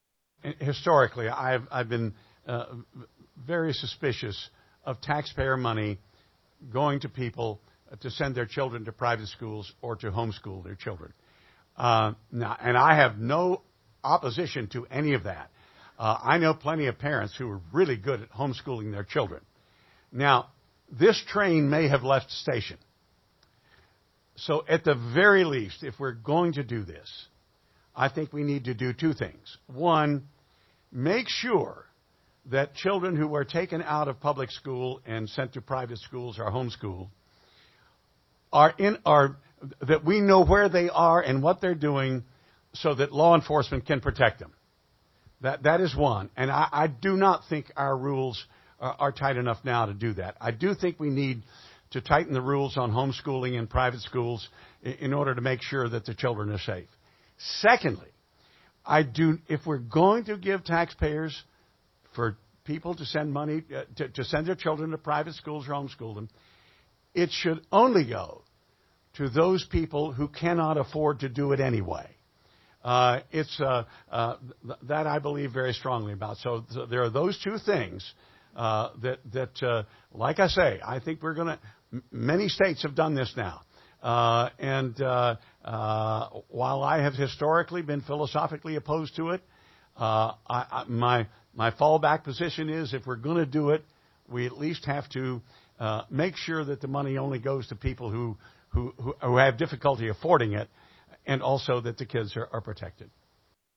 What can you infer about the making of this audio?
Jefferson Co. Senate Candidates Debate Education, Environment, Abortion - West Virginia Public Broadcasting